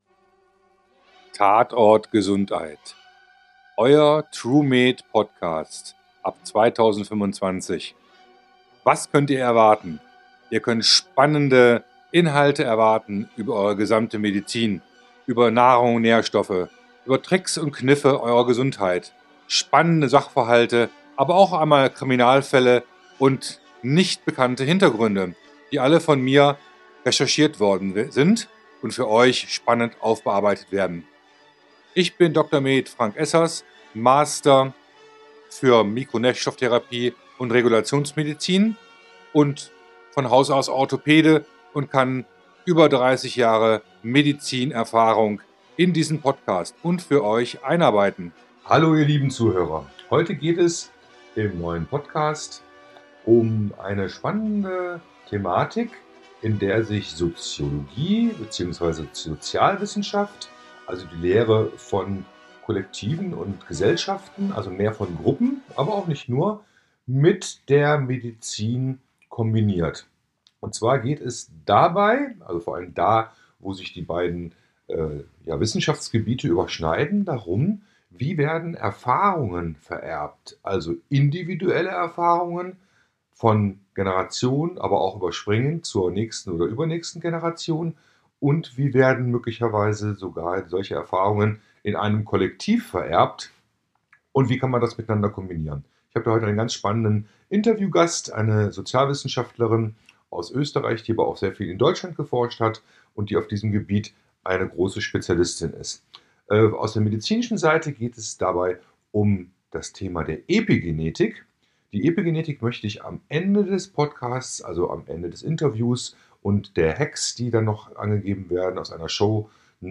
Zum Thema "EPIGENETIK", einem Thema das zunehmend in den Fokus der Gesundheits-Forschung gerät, heute die erste Folge im Experten-Interview.